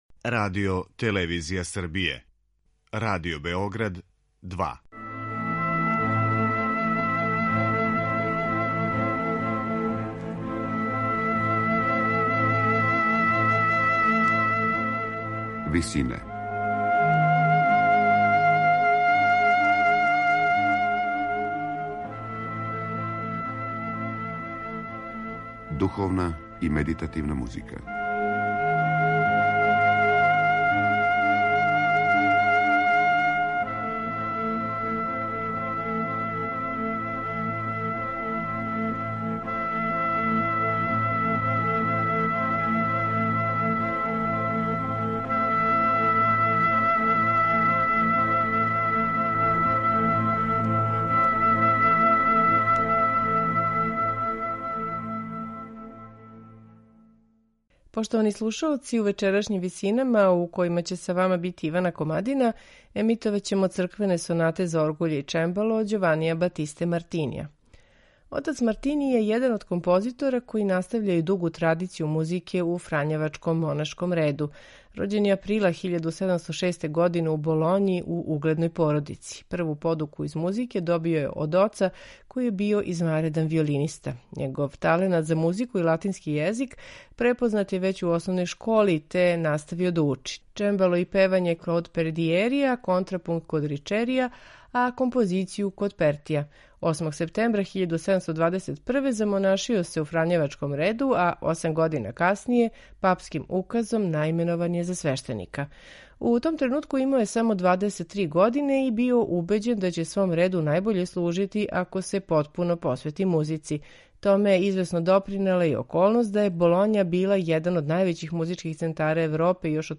У вечерашњим Висинама емитоваћемо Мартинијеве црквене сонате за оргуље и чембало.